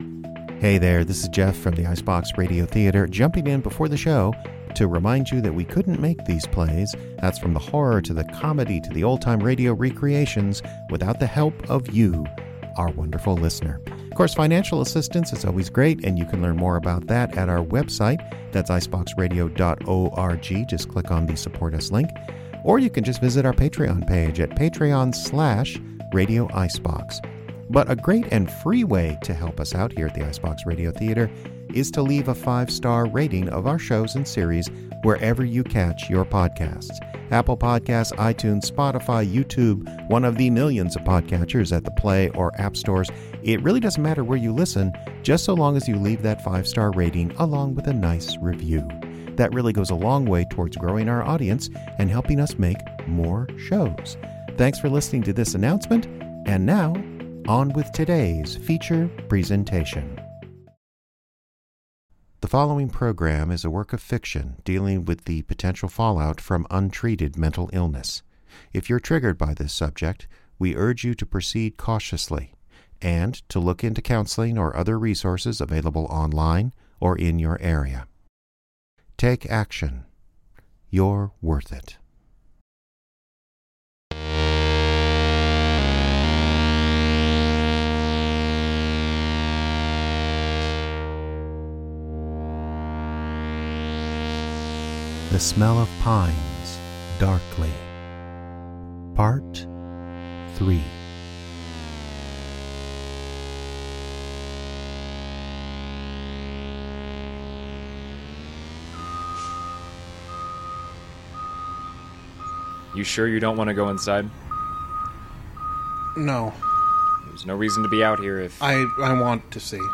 Other voices by the talented cast.
Some sound effects from the Freesound project at Freesound dot org.